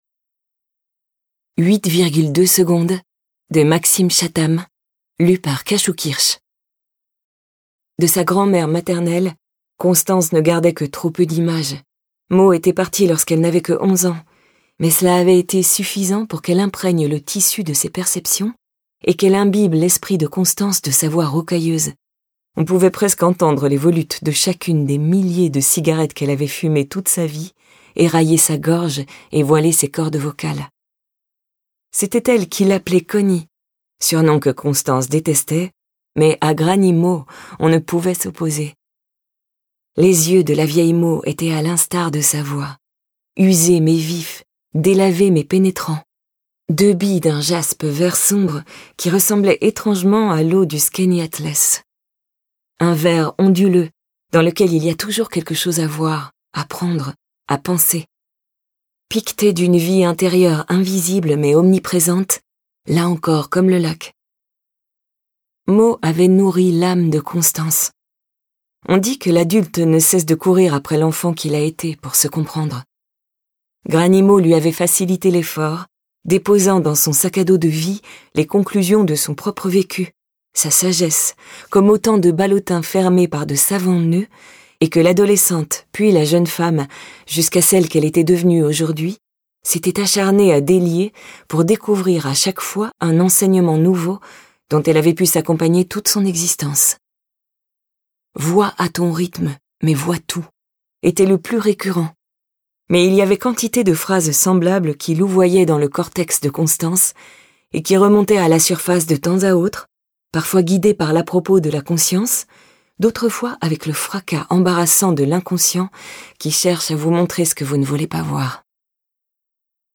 Diffusion distribution ebook et livre audio - Catalogue livres numériques
Entre New York et les grands lacs de la frontière canadienne, Maxime Chattam nous entraîne dans un suspense hitchcockien impossible à lâcher. Interprétation humaine Durée : 11H29 23 , 95 € Ce livre est accessible aux handicaps Voir les informations d'accessibilité